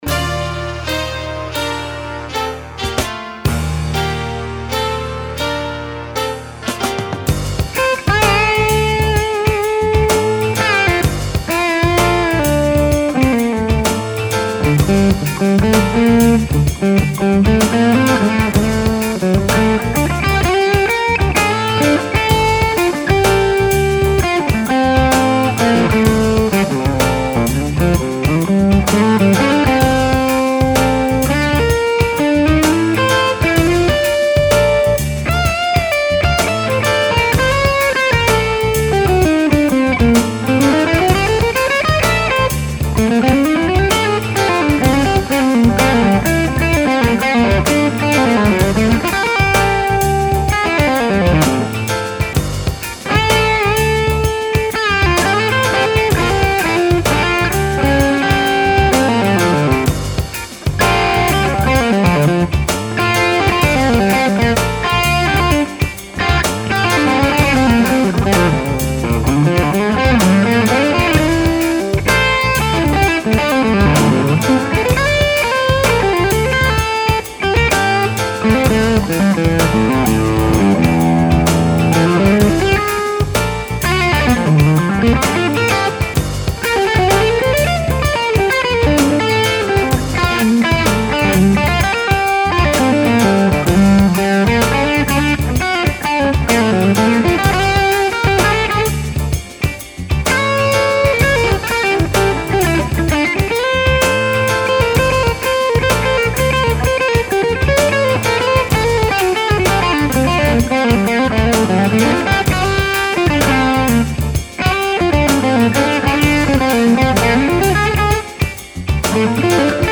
Skyline stack, PAB on, midboost on, HRM, Bluesmaster PI, 6L6 tubes.
SM57 on G1265
Volume was only on 2.
I like the 6L6 sounds better than the EL34, I like a little more "character" (read warmth) in the low mids.
Mic was dead on about an inch in from the cone edge. Close miced.